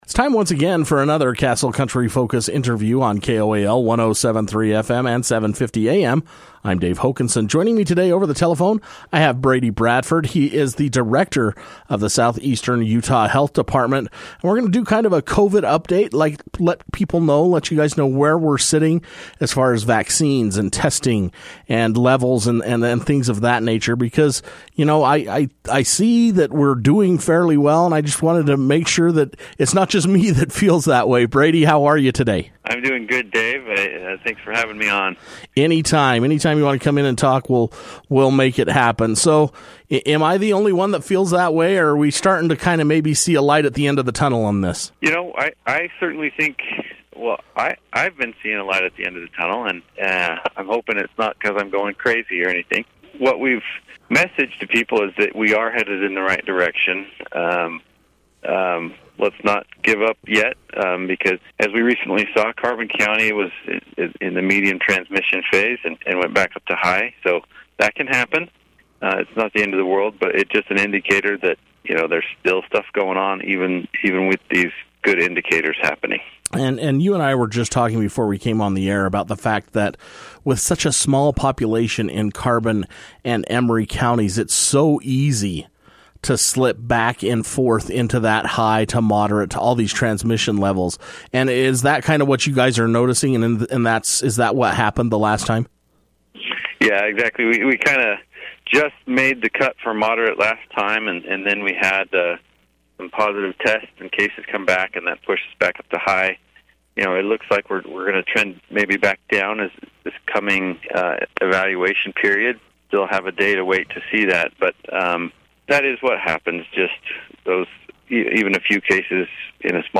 The Southeast Utah health Department Director Brady Bradford took time to speak over the telephone with Castle Country Radio about the latest COVID news taking place in our area.